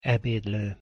Pronunciation Hu Ebédlő (audio/mpeg)